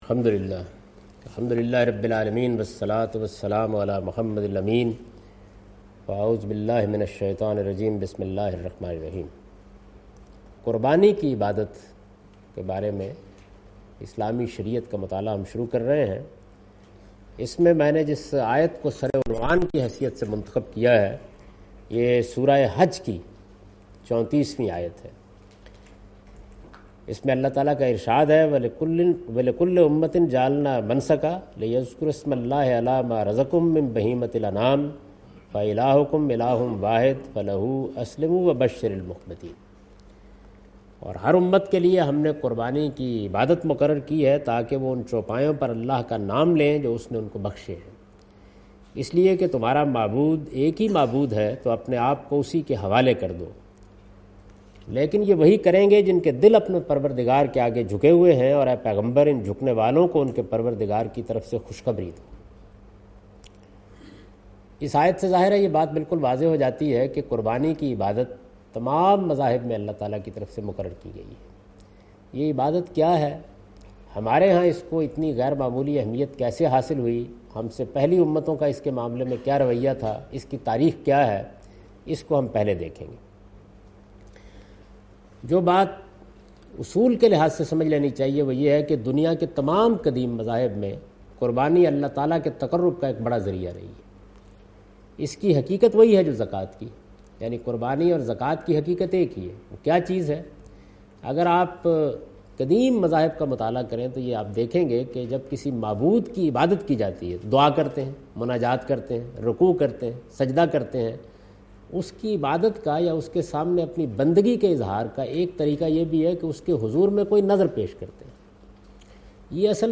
Philosophy, history, objectives and sharia (law) of Qurbani taught by Javed Ahmad Ghamidi from his book Meezan under Al Islam Course organized by Al Mawrid.